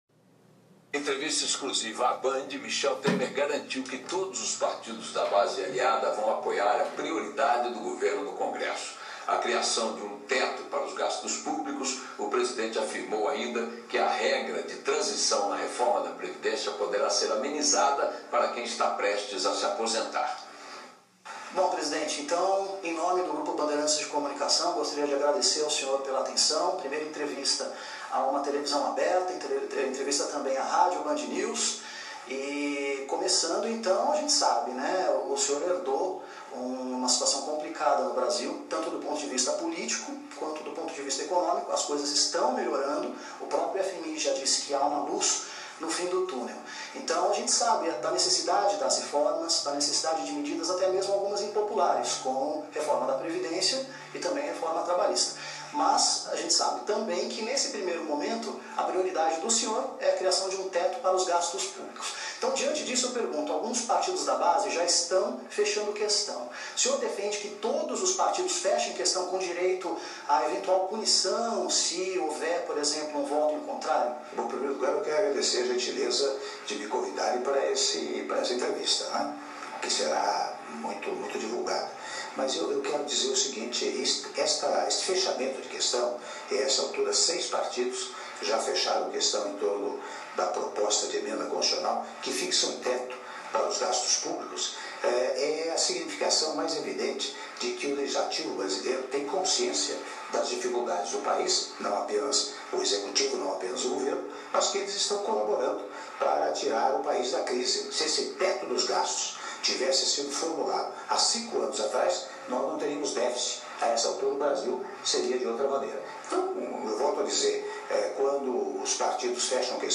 Áudio da entrevista concedida pelo presidente da República, Michel Temer, para a TV Band - (06min56s) - Brasília/DF